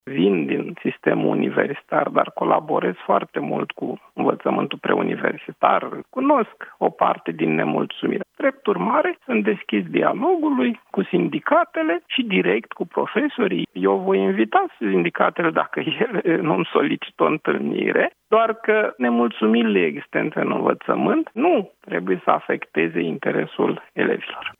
Ministrul Educației, Mihai Dimian: „Nemulțumirile existente în învățământ nu trebuie să afecteze interesul elevilor”